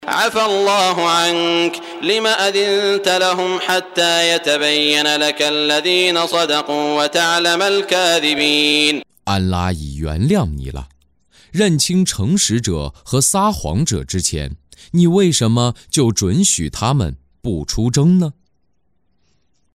中文语音诵读的《古兰经》第（讨拜）章经文译解（按节分段），并附有诵经家沙特·舒拉伊姆的朗诵